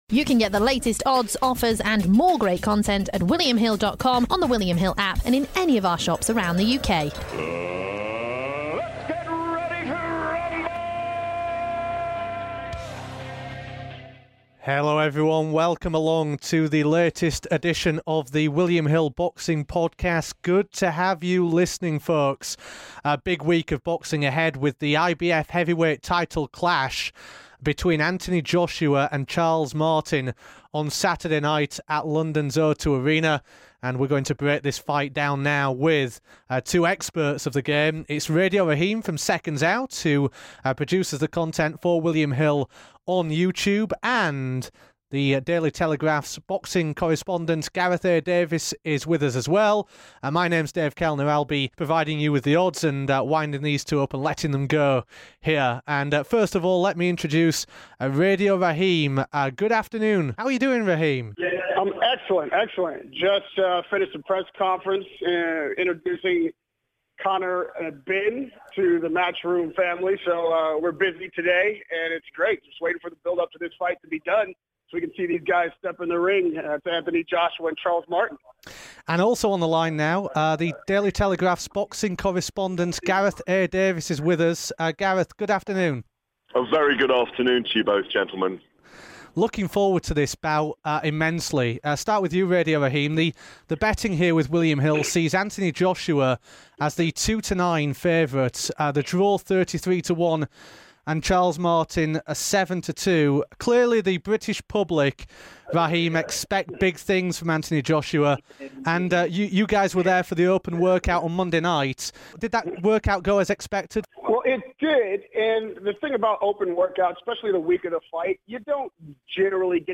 The trio go through the markets for the fight with both guests offering their best bets.